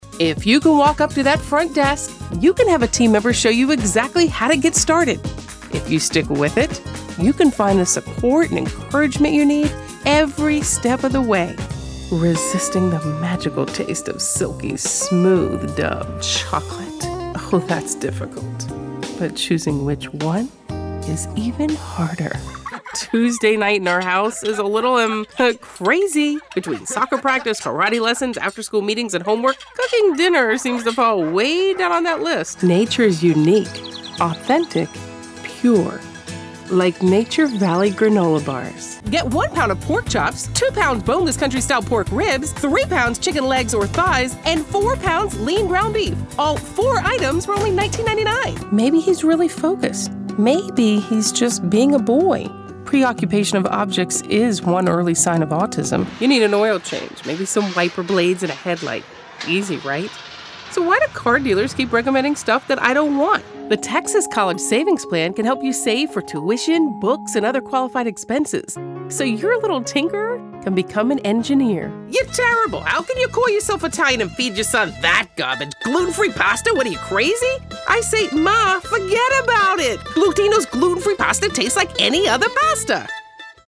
Commercial Voiceover
vo_commercial.mp3